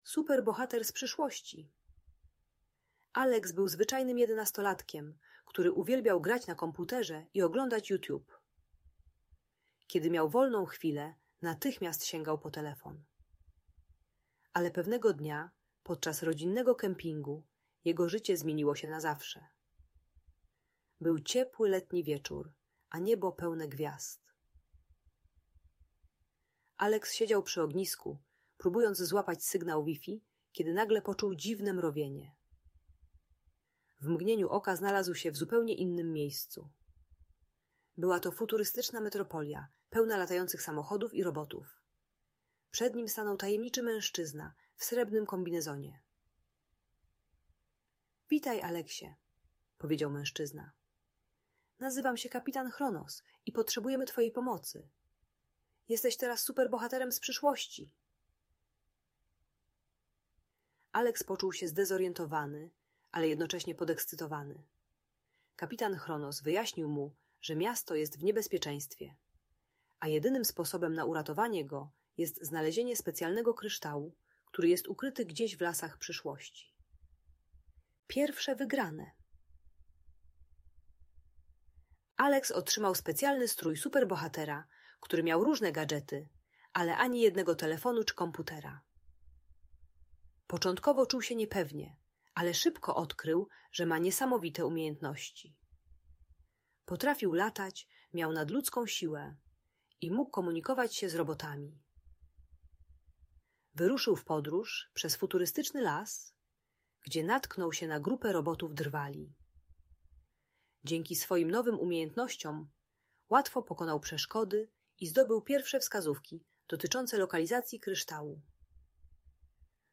Superbohater z Przyszłości - Niezwykła historia o Odwadze - Audiobajka